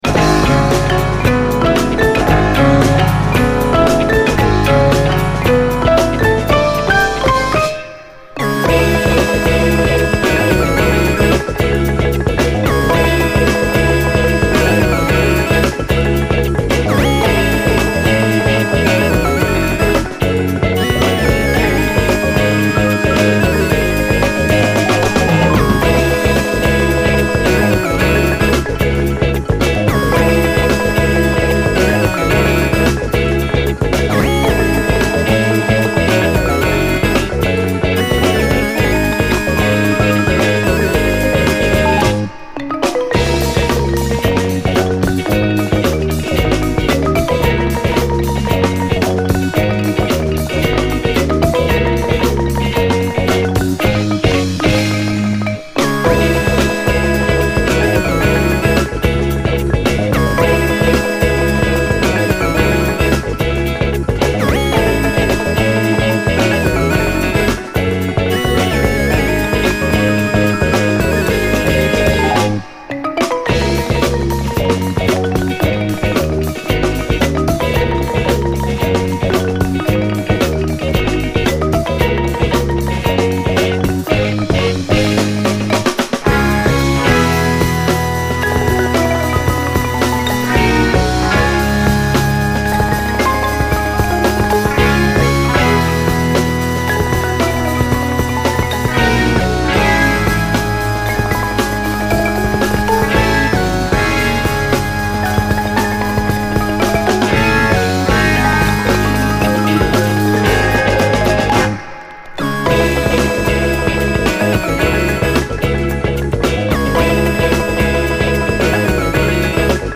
SOUL, 70's～ SOUL, SSW / AOR, ROCK
狂ったシンセが突き抜ける、異端レアグルーヴとも言えるキラー・ファンキー・チューン
プリAOR〜ブルーアイド・ソウル名盤！変化に富んだシンセ・アレンジがヤバい白人バンド！
中盤にはブレイクもあり！